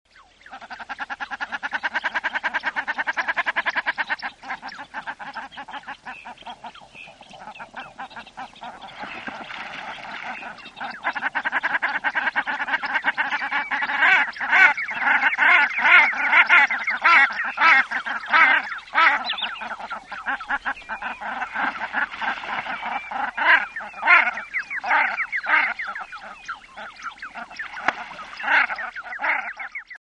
Ohar - Tadorna tadorna
głosy